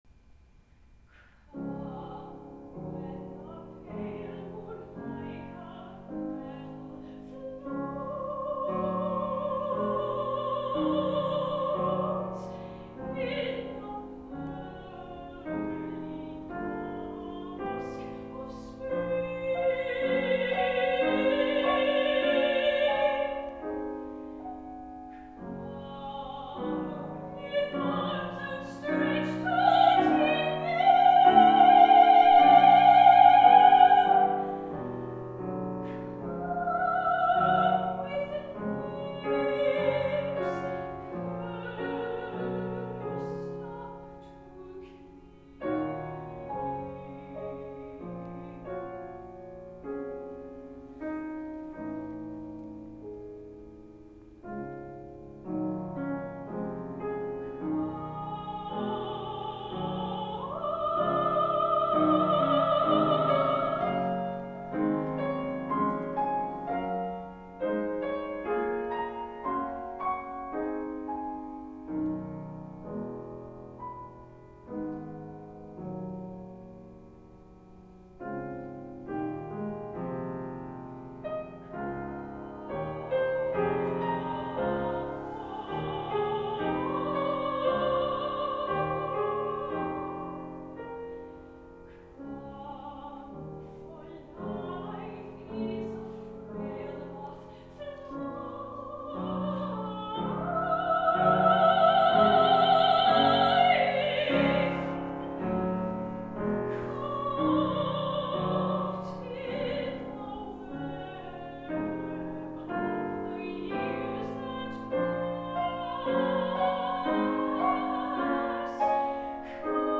Voice and Piano (High and Medium version)